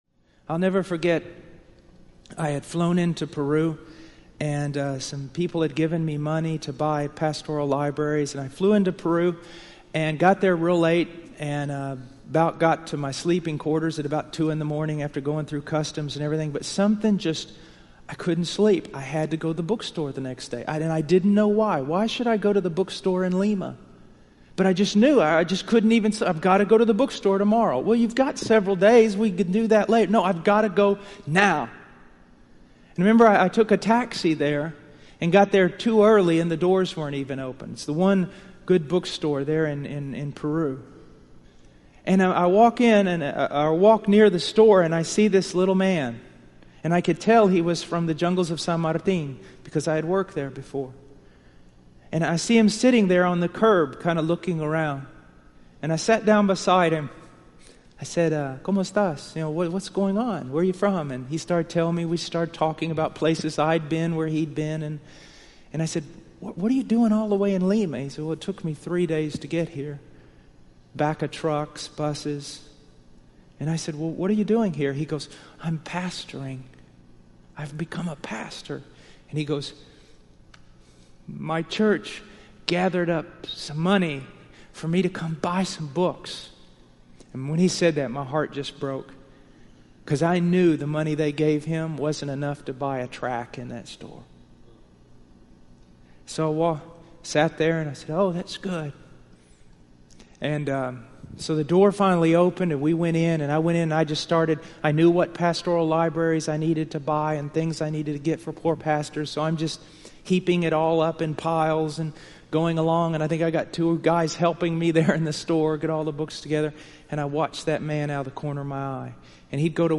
This excerpt is from the 2006 HeartCry Conference .